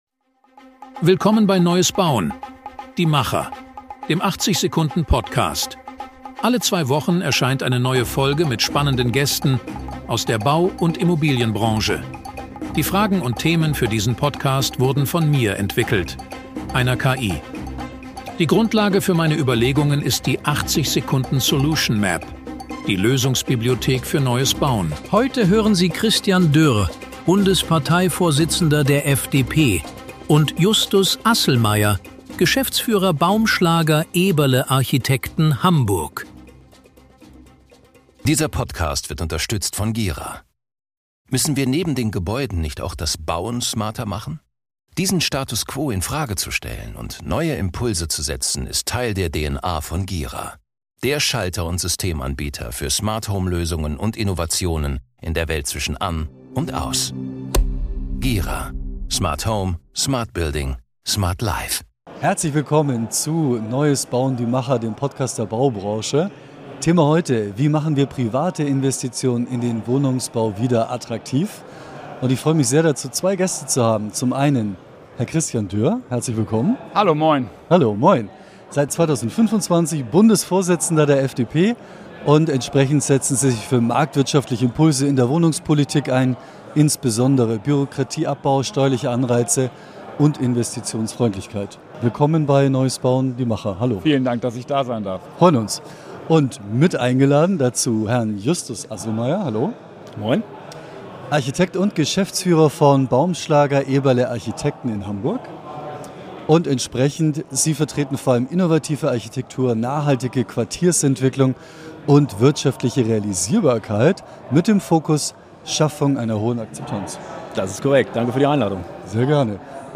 In dieser Folge von „Neues Bauen – Die Macher“ sprechen wir live aus Berlin mit